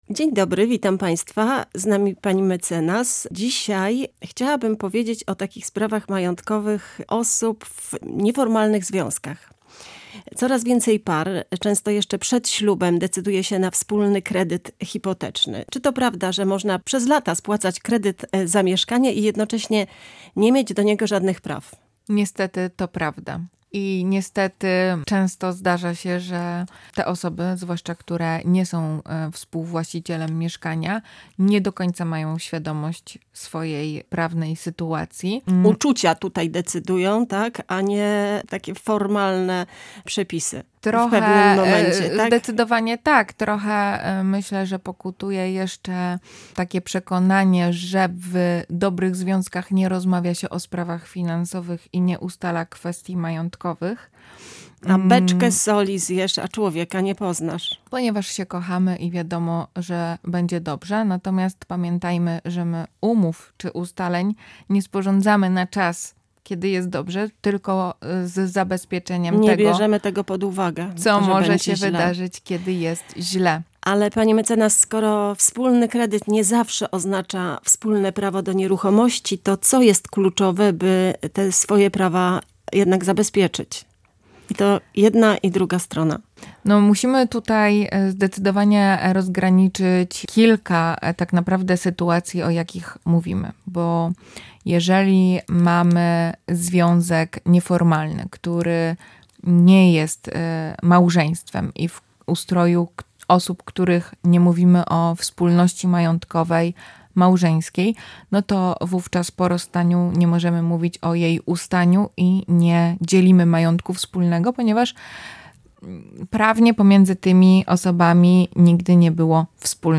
W audycji "Prawo na co dzień" omawiamy temat wspólnego majątku oraz kredytu, na przykład hipotecznego, w związkach nieformalnych.